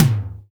GROOVE TOM.wav